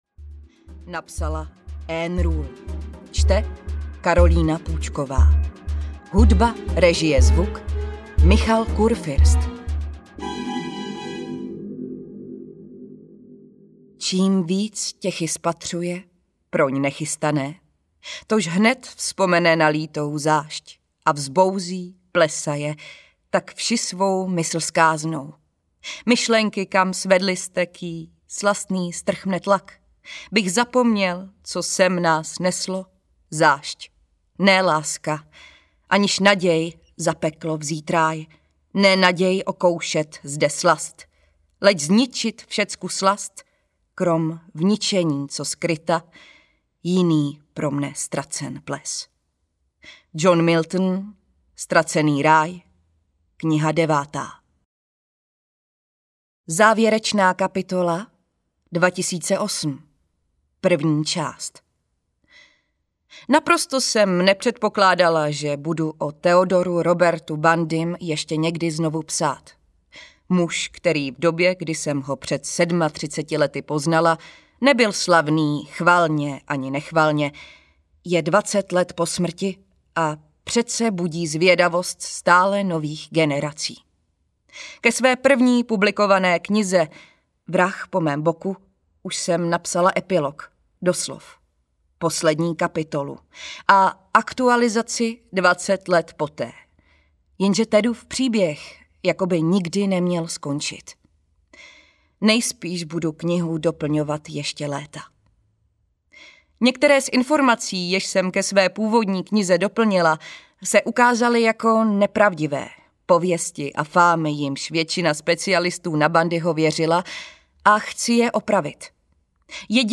Ted Bundy, vrah po mém boku audiokniha
Ukázka z knihy